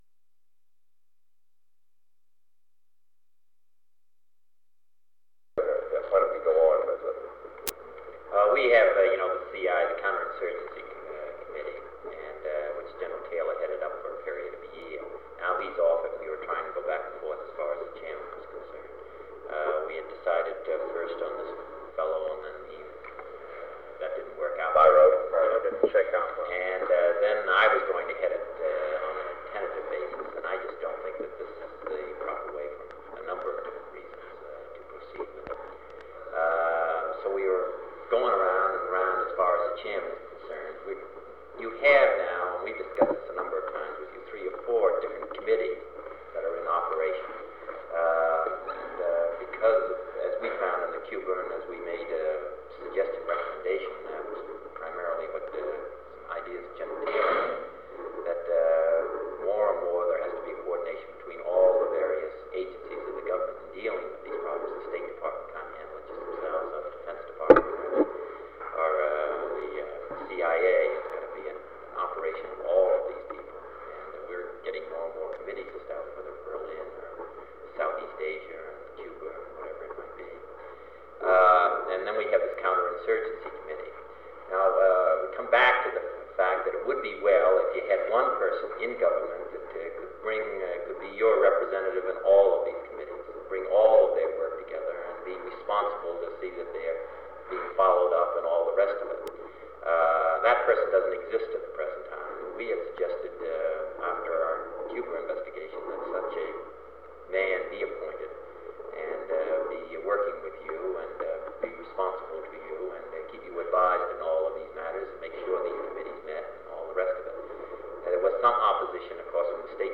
Secret White House Tapes | John F. Kennedy Presidency Meeting on Interdepartmental Coordination of Foreign Policy Rewind 10 seconds Play/Pause Fast-forward 10 seconds 0:00 Download audio Previous Meetings: Tape 121/A57.